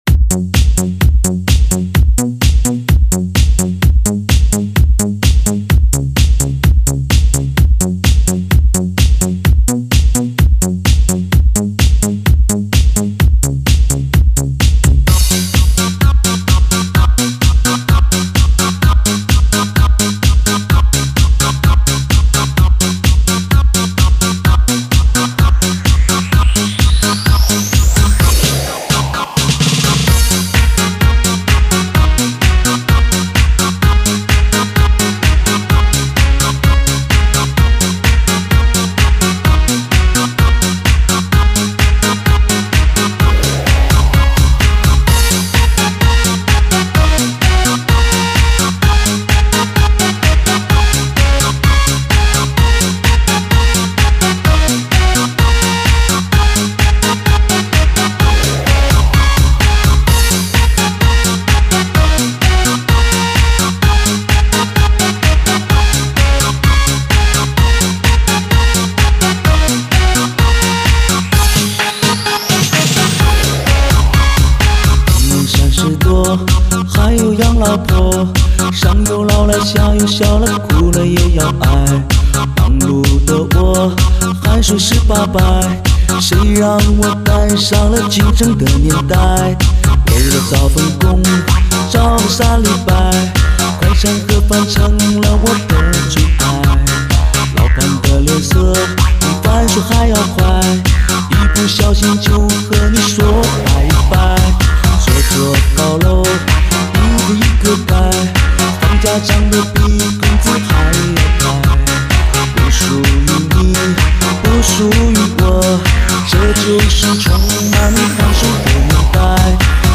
唱片类型：汽车音乐